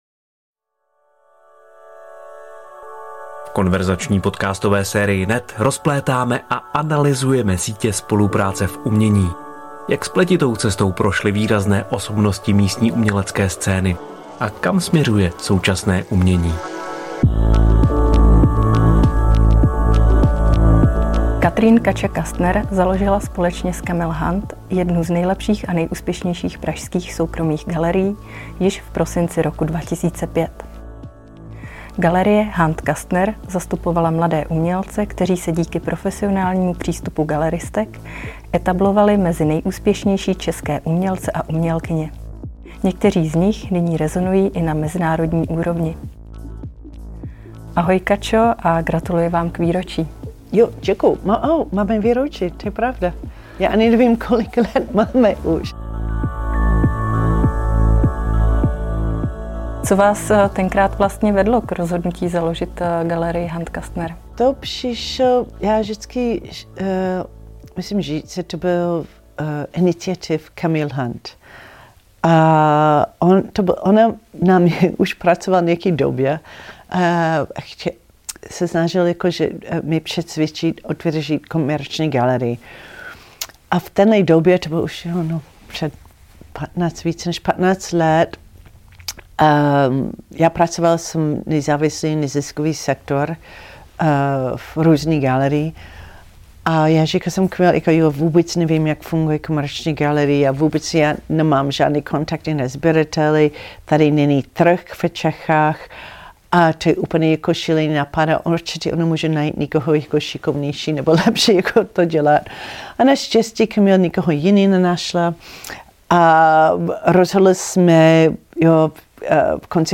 V konverzační podcastové sérii NET rozplétáme a analyzujeme sítě spolupráce v umění.